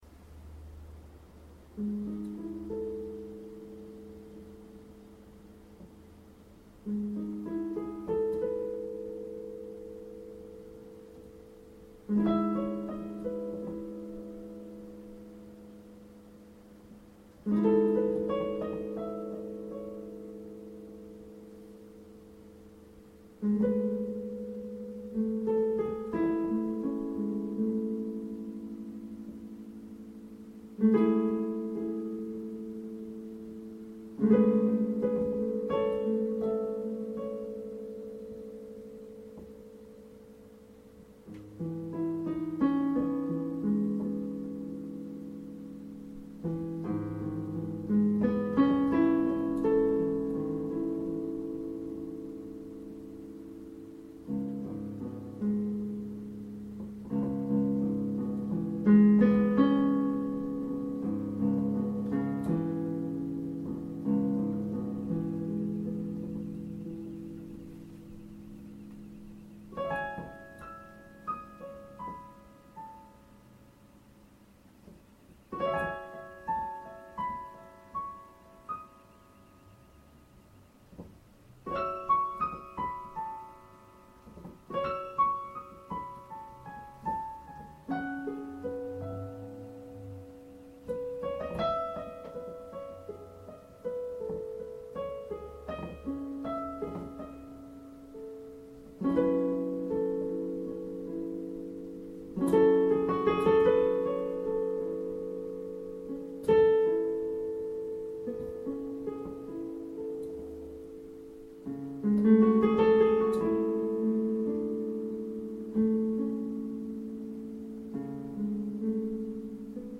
late night piano doodle
arty farty piano stuff